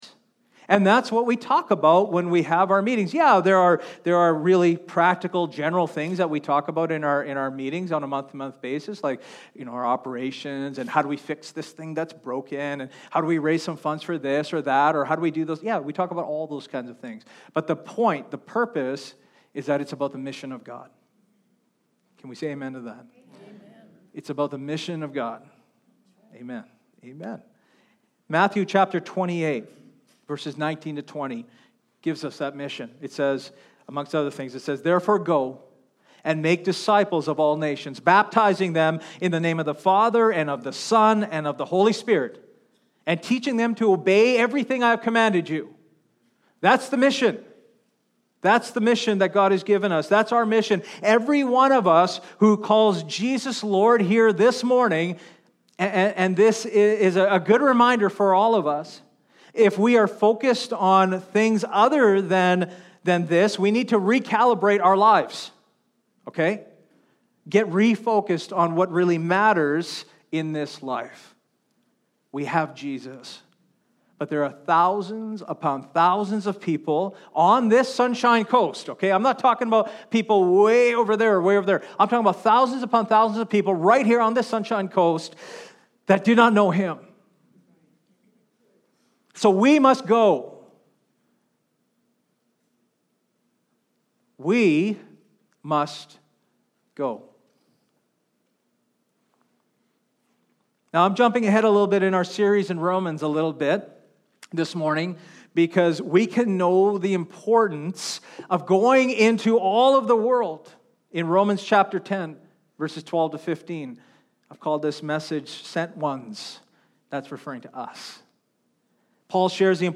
Sermons | Christian Life Assembly